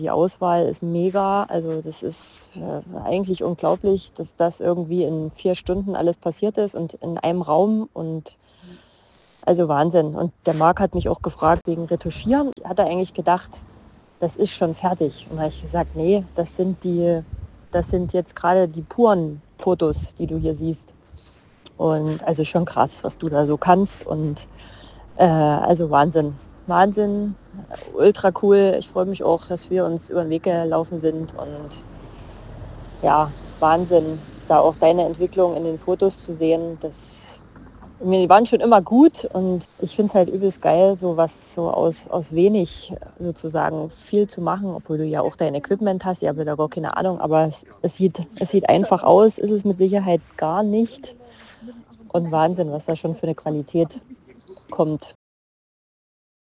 Hör Dir am besten auch eine Kundinnenstimme nach einem Fotoshooting bei mir an: